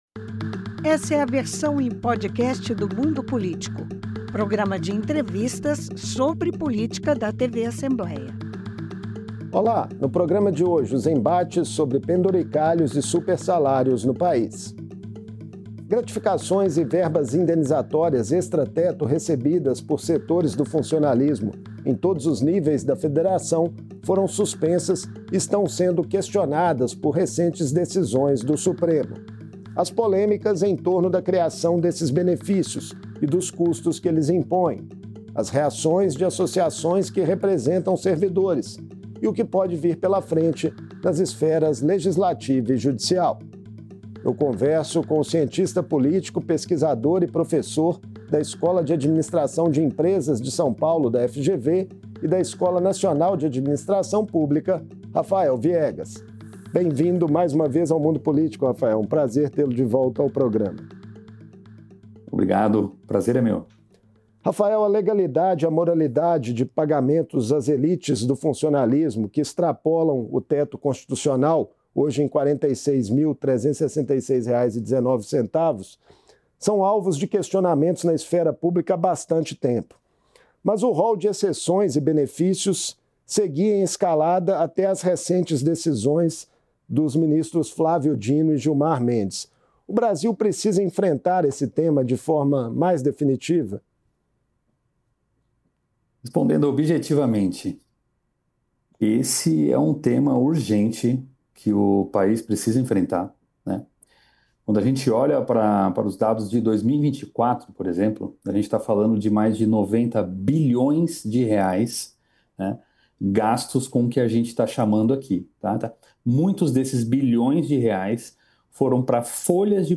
O debate sobre limitação da remuneração dos agentes públicos existe há pelo menos seis décadas no Brasil. Agora o STF começa a analisar decisões de ministros sobre o assunto e deve julgar o caso até final de março. Em entrevista